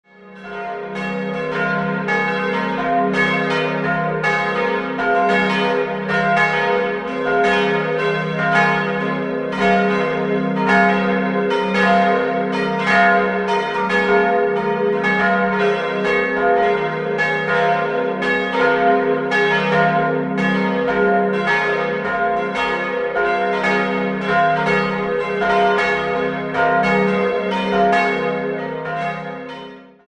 Die heutige geräumige Kirche, deren Vorgängerbauten bis ins Jahr 1000 zurückreichen, wurde in den Jahren 1670/80 errichtet und im Stil des Barock ausgestattet. 4-stimmiges ausgefülltes F-Moll-Geläute: f'-as'-b'-c'' Nähere Daten liegen nicht vor.